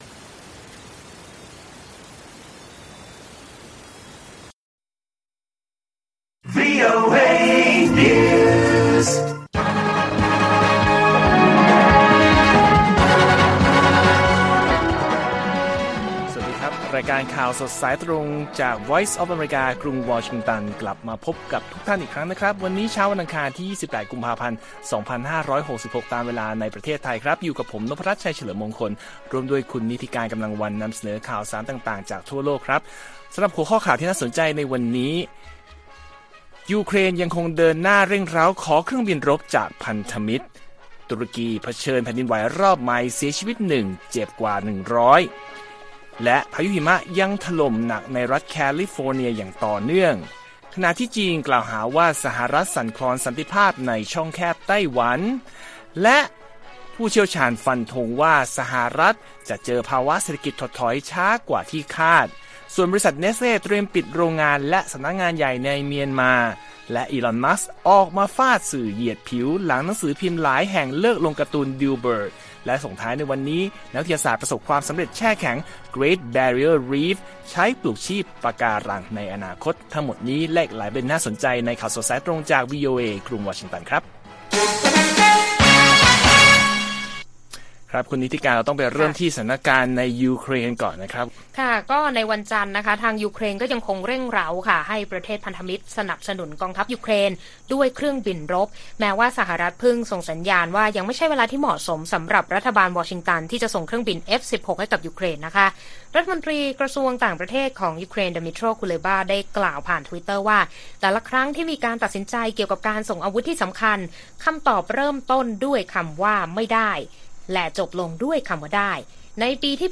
ข่าวสดสายตรงจากวีโอเอไทย 8:30–9:00 น. 28 ก.พ. 2566